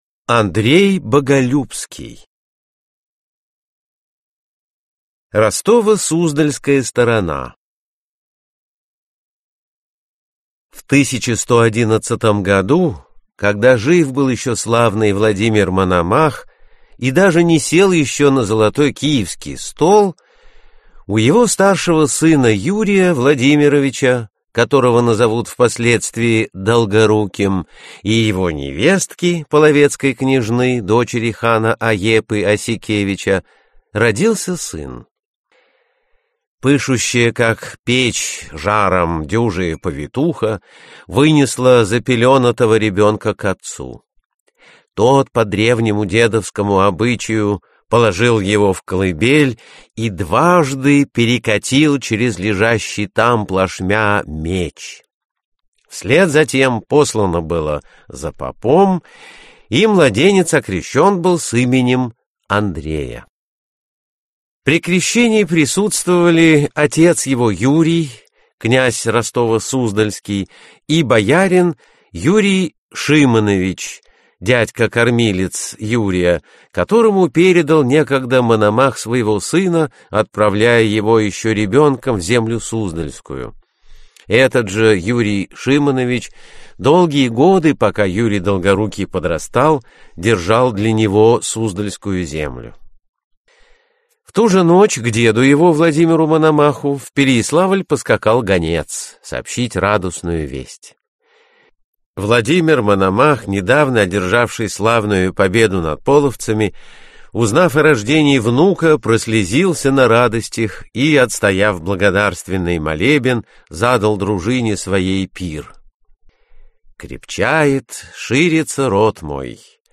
Аудиокнига Заступники земли Русской. Часть 2 | Библиотека аудиокниг